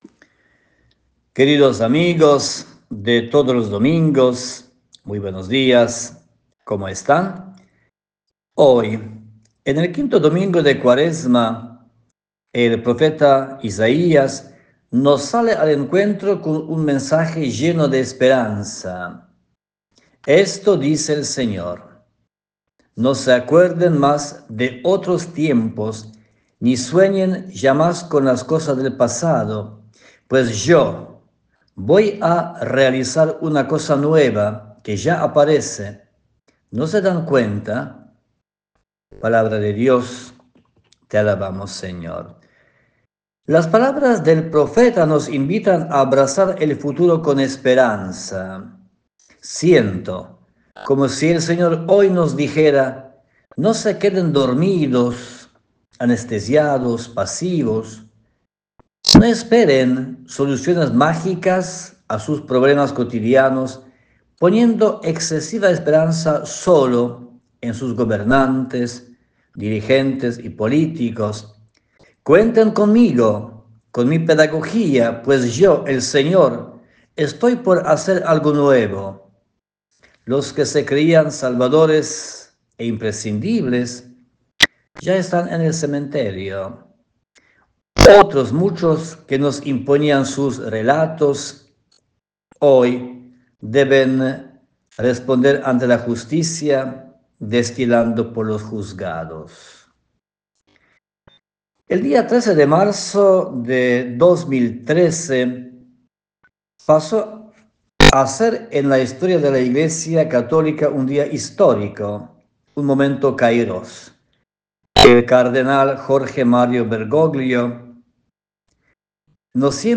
La reflexión dominical